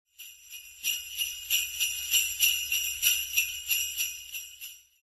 Sleigh Bells (Short Version)
SleighBells-ShortVersion.mp3